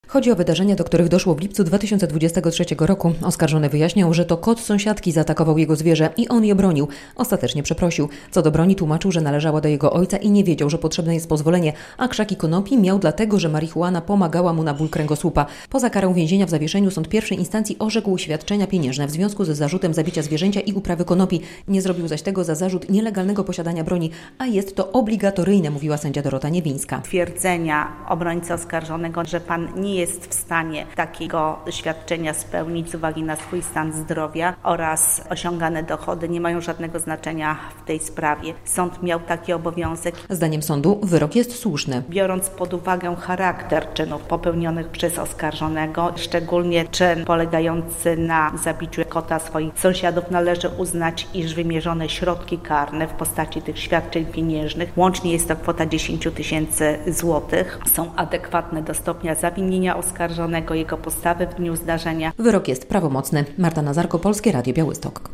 Wyrok za zabicie kota - relacja